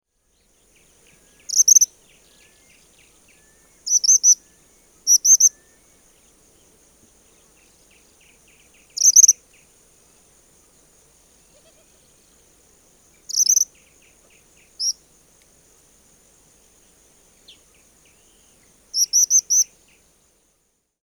Tyrannus melancholicus
Aliás, o nome popular suiriri vem do canto da espécie, ou seja, é um nome onomatopaico.
Nome em Inglês: Tropical Kingbird
Aprecie o canto do
Suiriri
suiriri.mp3